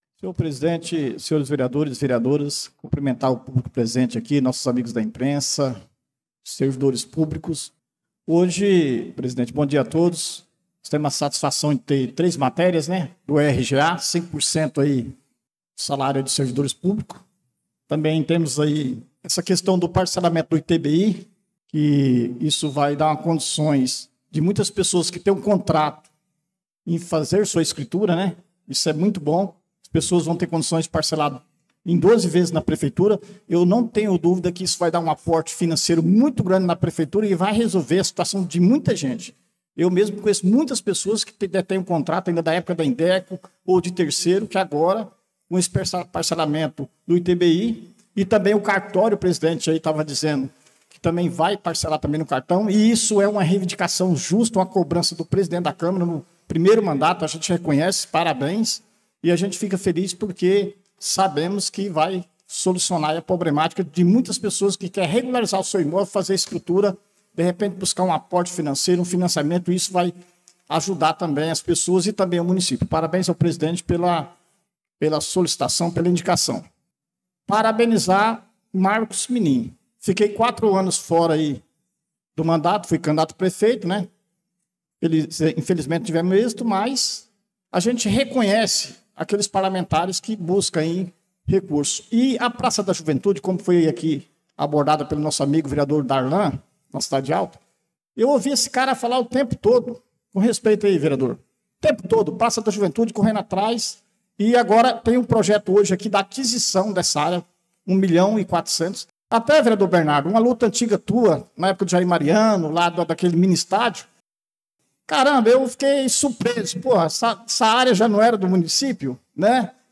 Pronunciamento do vereador Dida Pires na Sessão Ordinária do dia 11/02/2025